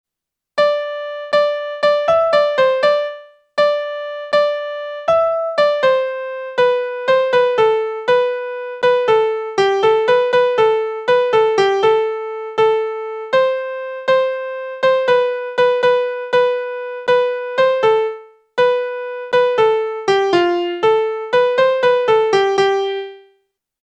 We hear that there are the makings of a melody, but some of the rhythm is clearly incorrect.